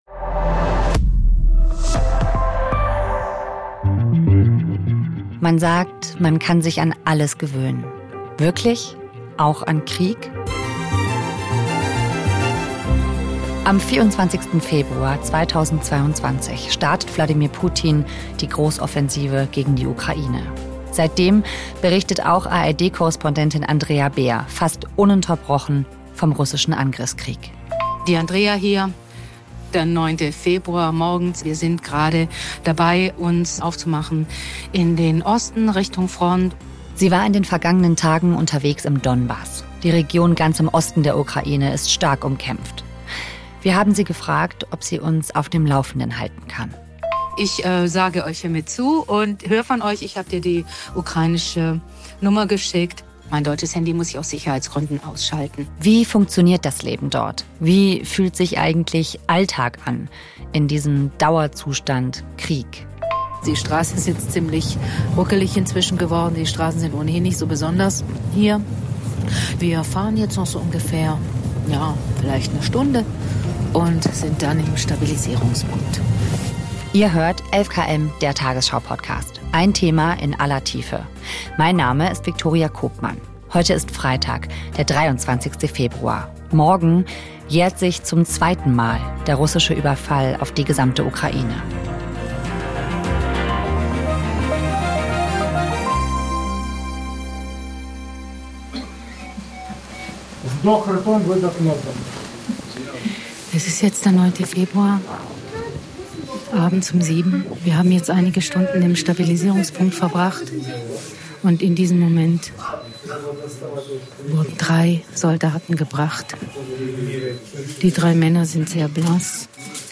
Eine Woche lang schickt sie Sprachnachrichten aus dem Krieg und berichtet von Begegnungen und ihren Erlebnissen.